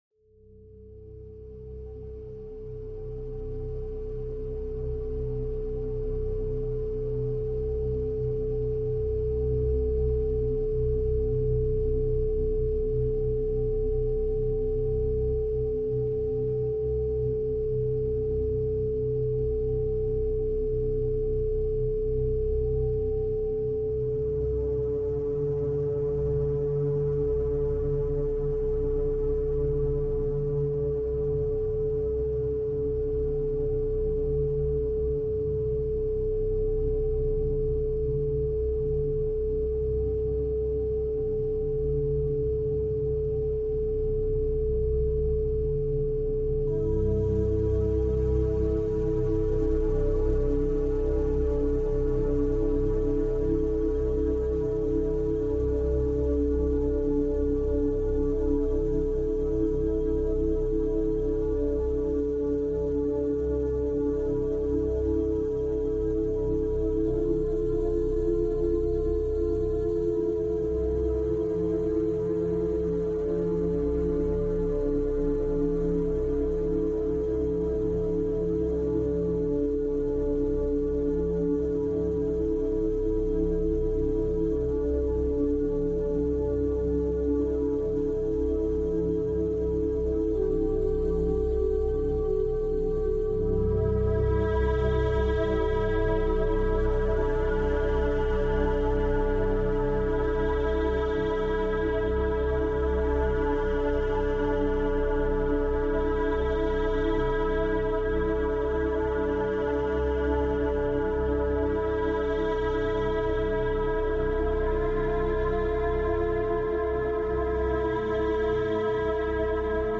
Forêt matinale douce · méthode éprouvée 2 heures orientée projets